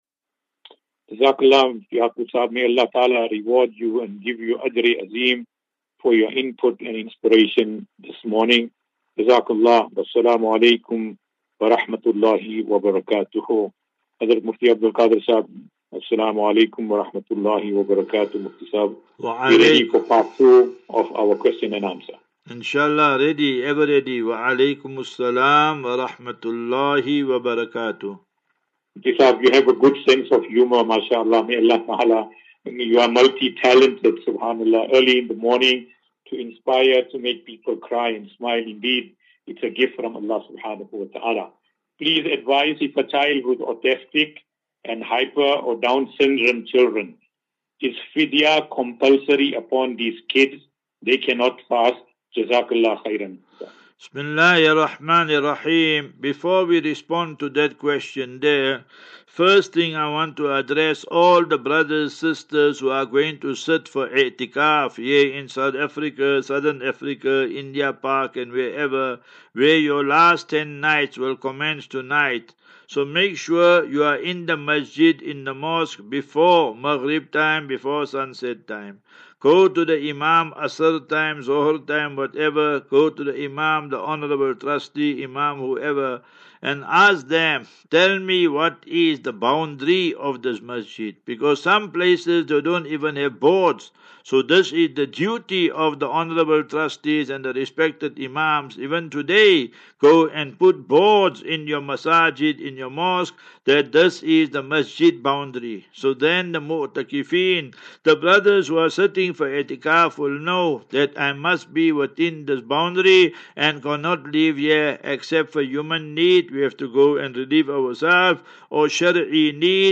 As Safinatu Ilal Jannah Naseeha and Q and A 31 Mar 31 March 2024.